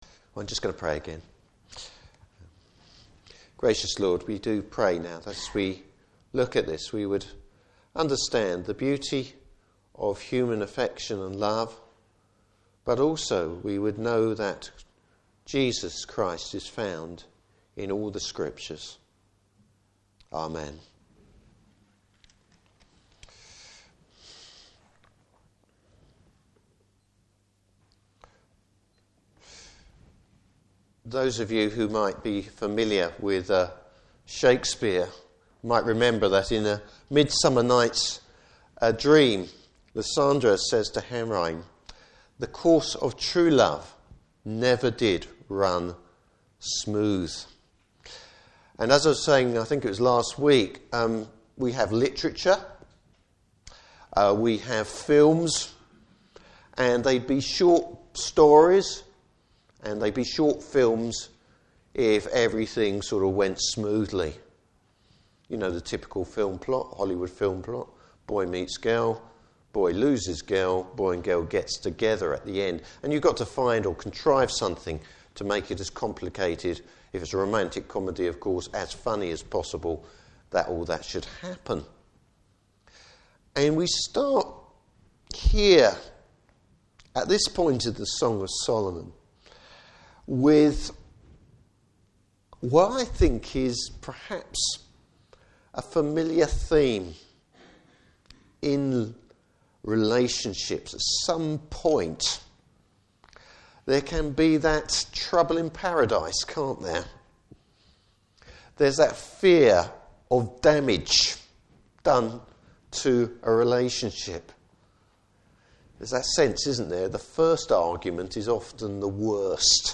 Service Type: Morning Service Bible Text: Song of Solomon 5:2-6:13.